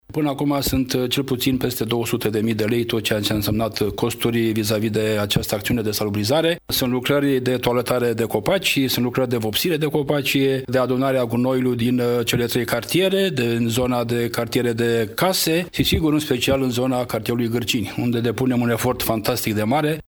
Primarul Municipiului Săcele, Virgil Popa.